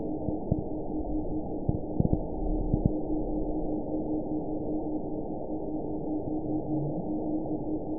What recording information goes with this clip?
event 921957 date 12/23/24 time 08:54:43 GMT (4 months, 1 week ago) score 9.18 location TSS-AB04 detected by nrw target species NRW annotations +NRW Spectrogram: Frequency (kHz) vs. Time (s) audio not available .wav